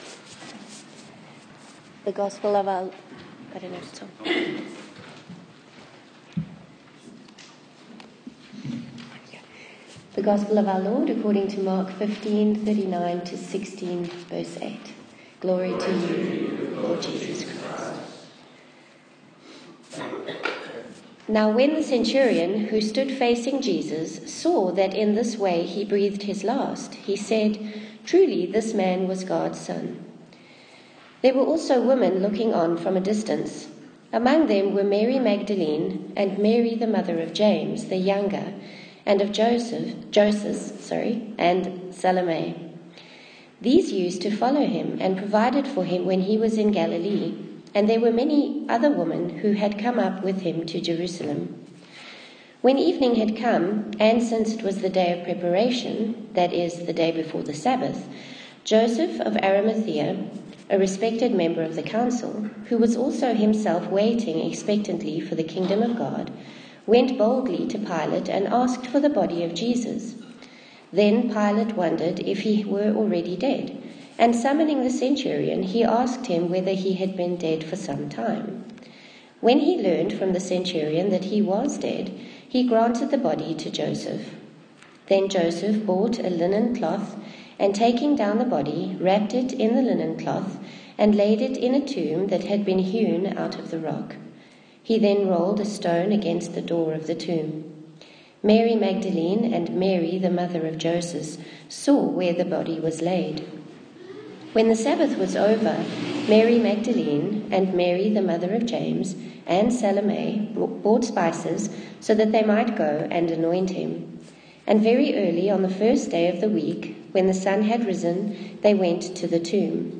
Passage: Mark 16:1–8 Service Type: Sunday morning service